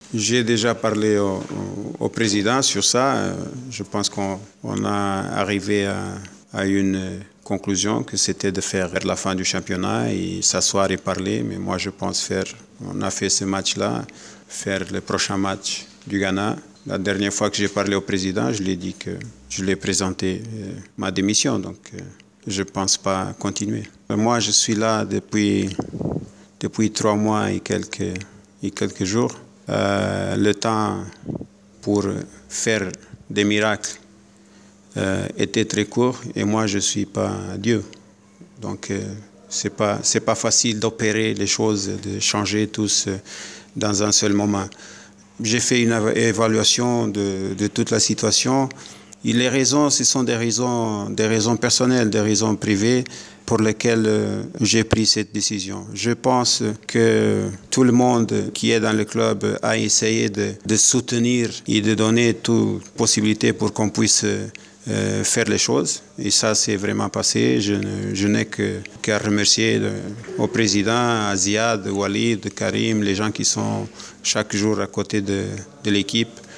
جوزي ديمواريس : مدرب الترجي الرياضي التونسي